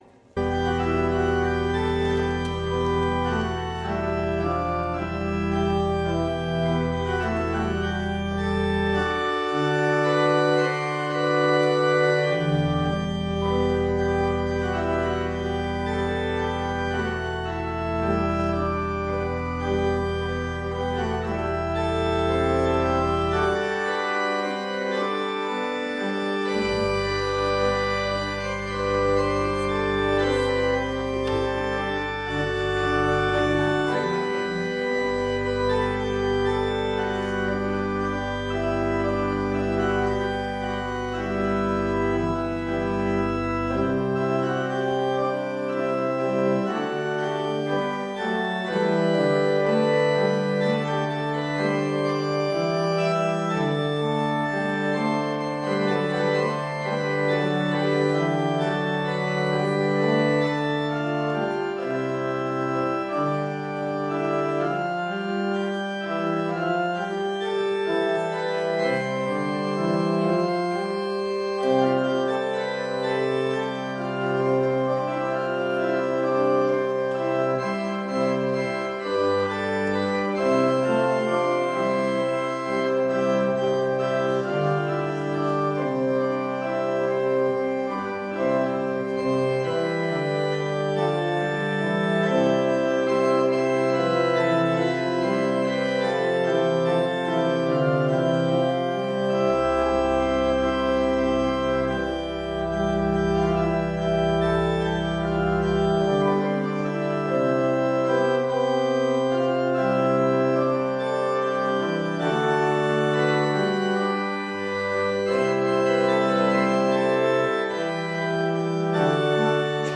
Gottesdienst vom 2. Juli